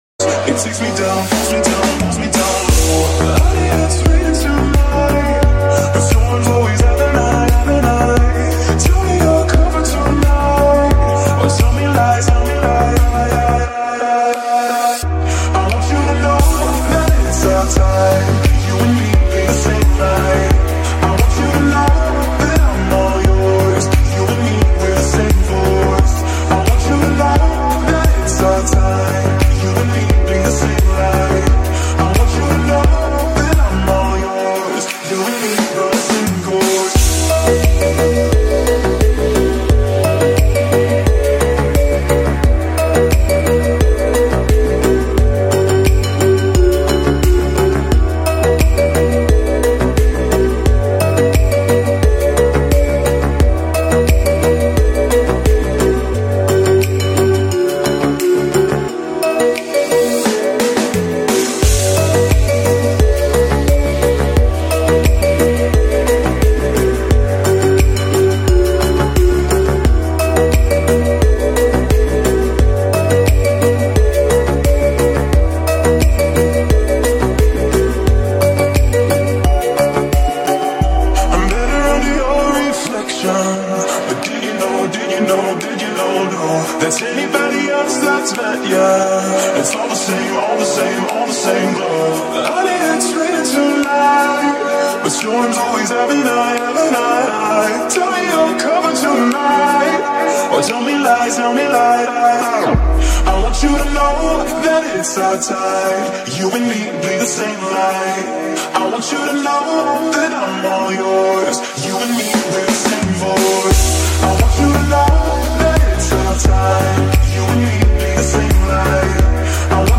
🚨 Taking Off From 🇺🇸 Sound Effects Free Download